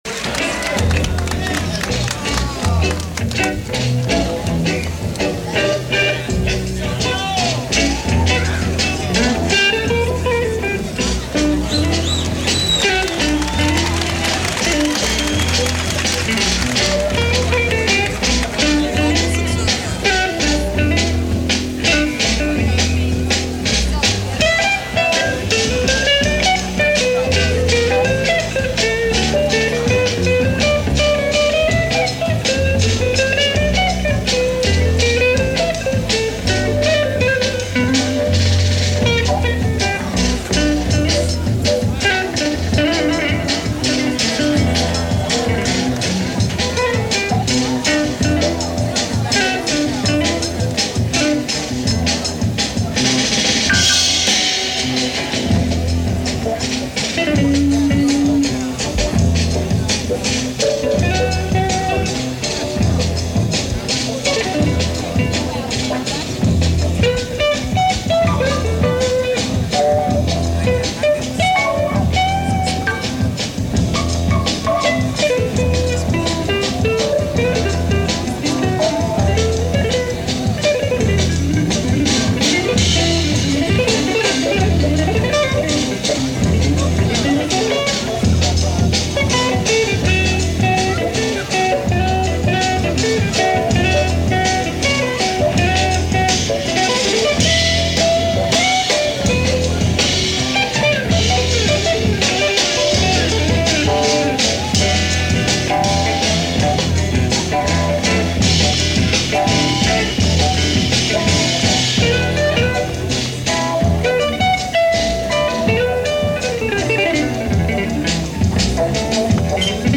guitar
tenor sax, soprano sax, and flute
trumpet
keyboards
drums